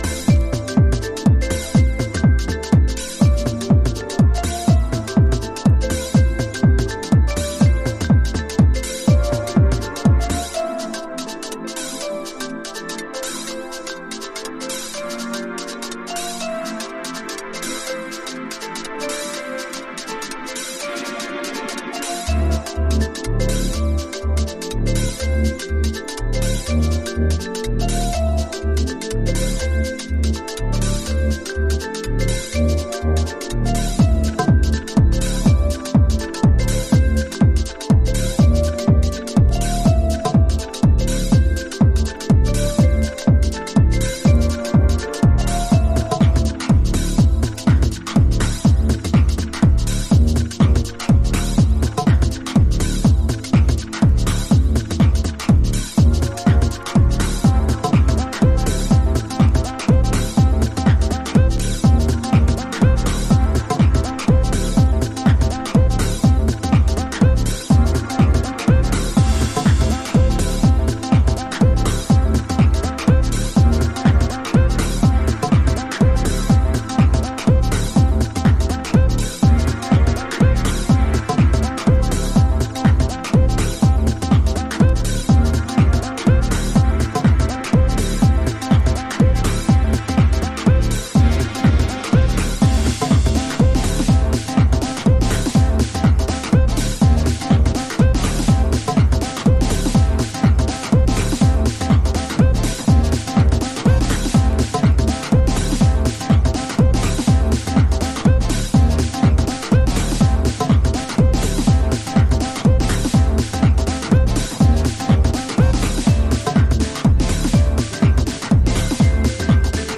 House / Techno
3/4ビートと鍵盤の嵐でグイグイ引き込む、UK産らしいポスト・デトロイトハウス。